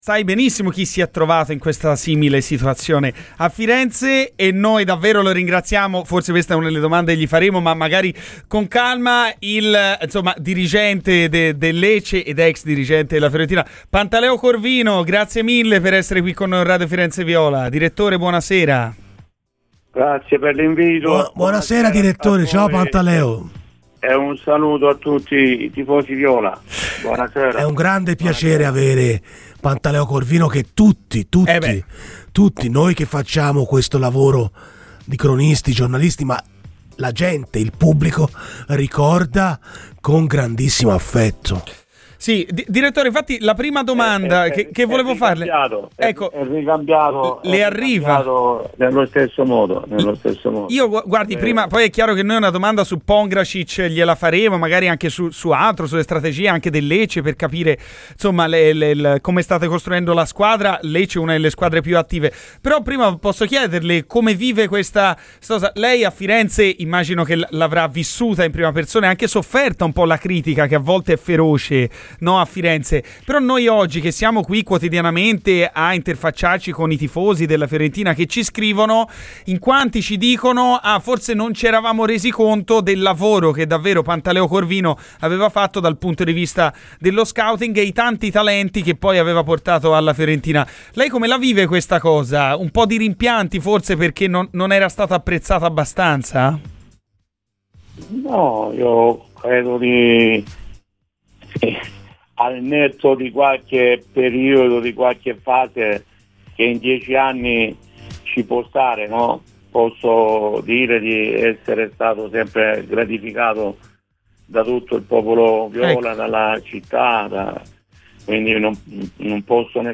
Il Direttore Sportivo del Lecce, ed ex D.S. della Fiorentina, Pantaleo Corvino è intervenuto ai microfoni di Radio FirenzeViola durante la trasmissione "Garrisca al vento" per parlare, oltre che di mercato, del nuovo acquisto gigliato, ovvero l'ex giallorosso Marin Pongracic.